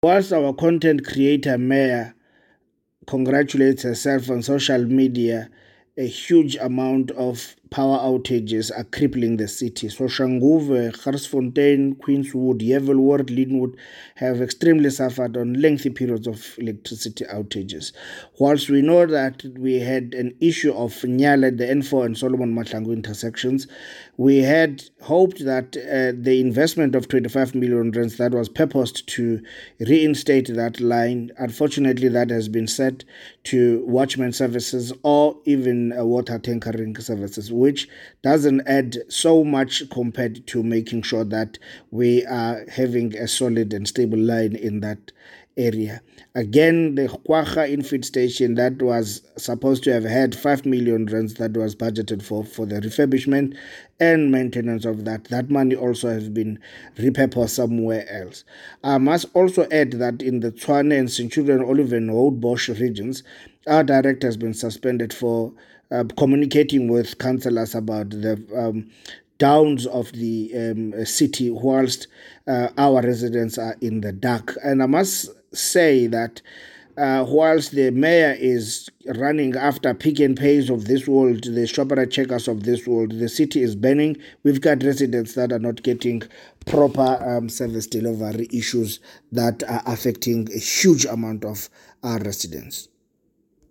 Note to Editors: Please find English and Tswana soundbites by Cllr Themba Fossi